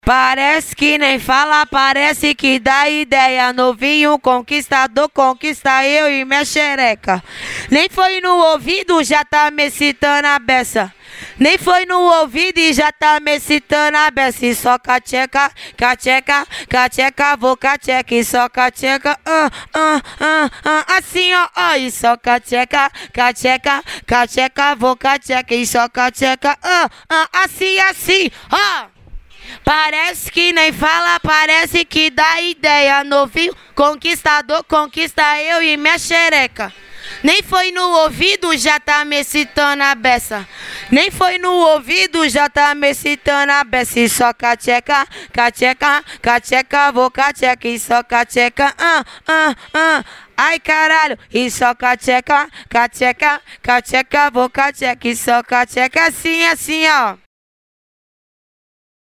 Acapellas de Funk